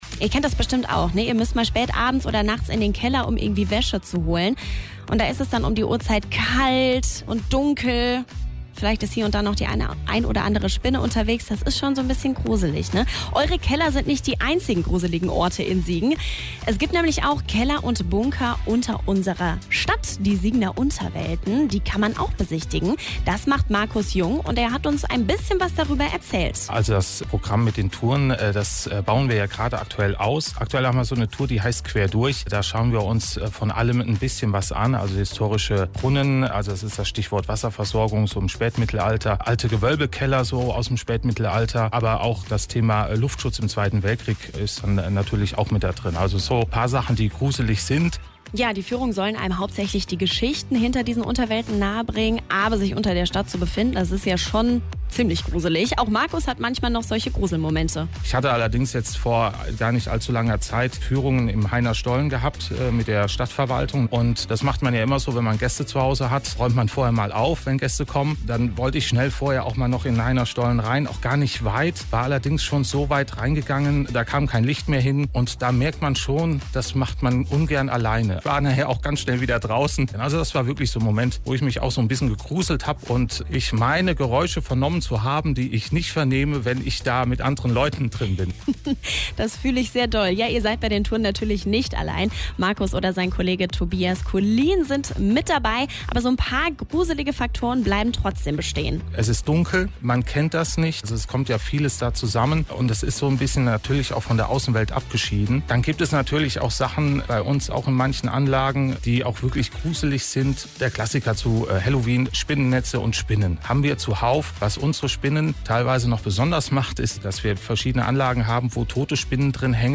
2025 - Interview zum Thema "Gruseln"